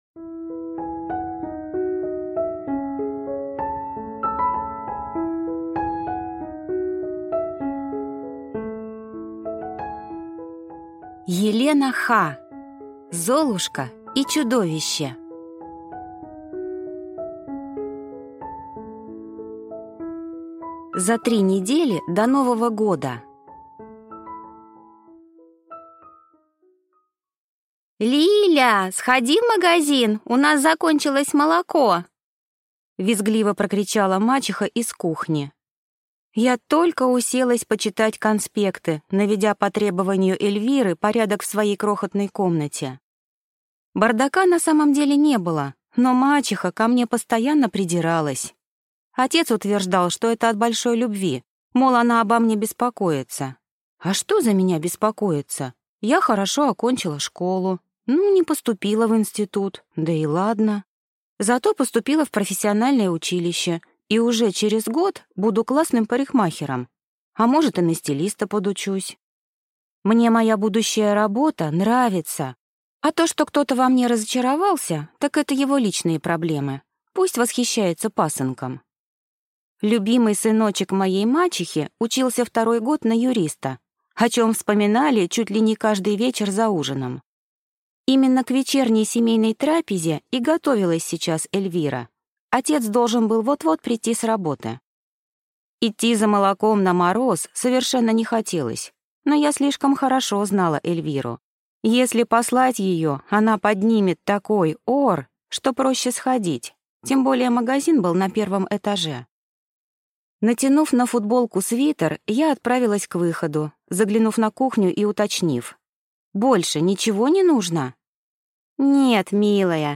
Аудиокнига Золушка и чудовище | Библиотека аудиокниг